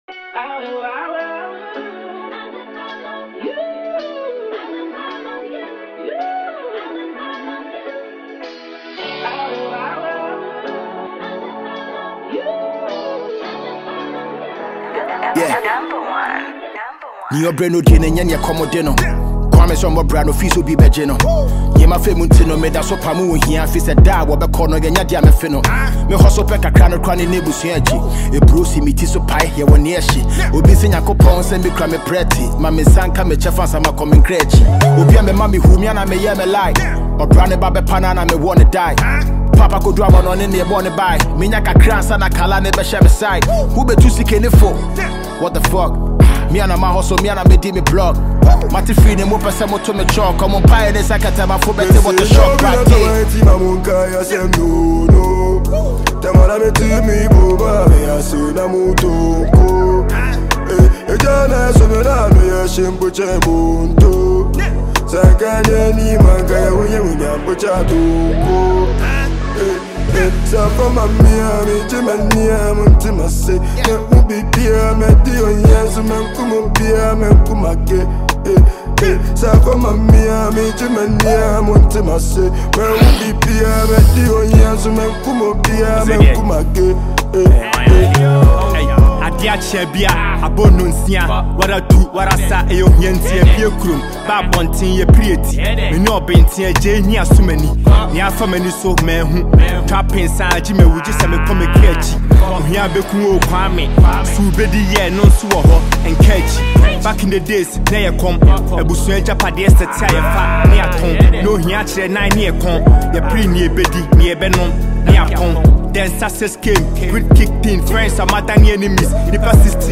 Heavyweight Ghanaian rapper